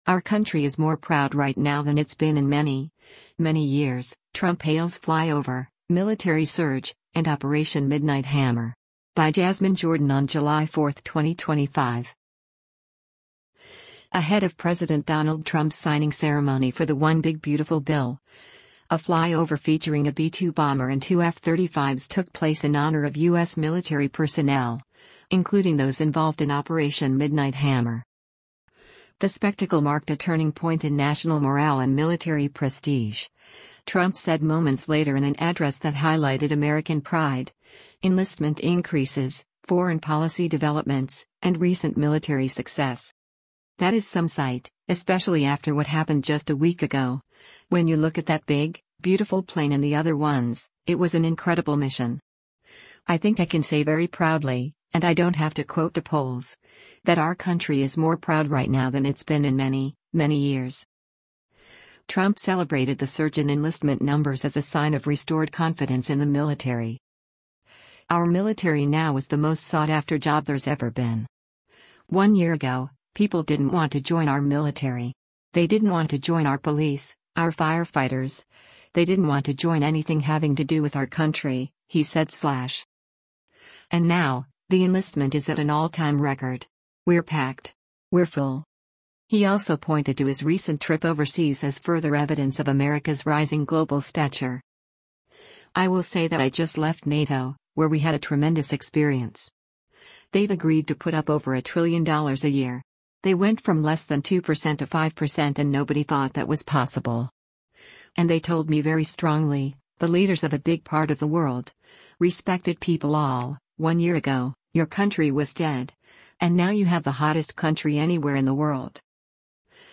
Ahead of President Donald Trump’s signing ceremony for the One Big Beautiful Bill, a flyover featuring a B-2 bomber and two F-35s took place in honor of U.S. military personnel, including those involved in Operation Midnight Hammer.
The spectacle marked a turning point in national morale and military prestige, Trump said moments later in an address that highlighted American pride, enlistment increases, foreign policy developments, and recent military success.